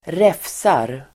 Uttal: [²r'ef:sar]